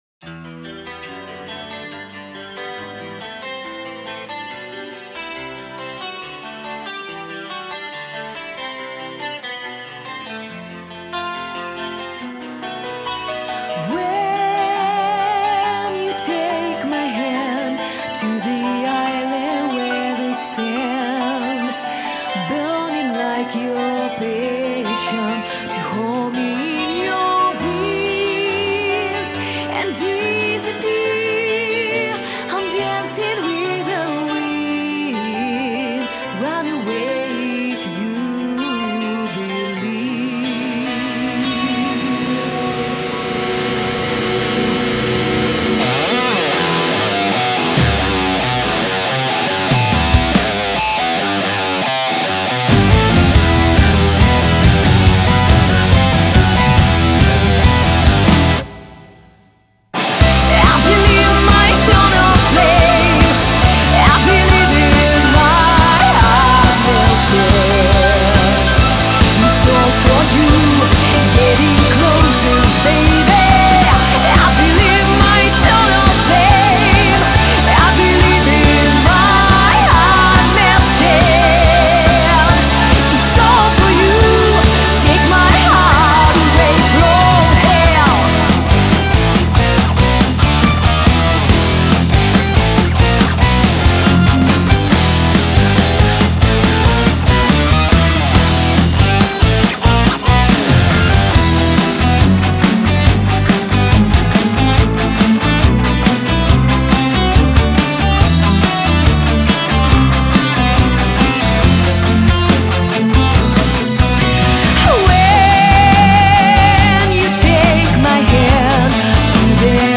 вокал
гитара, бэк-вокал
бас, бэк-вокал
клавишные
ударные, перкуссия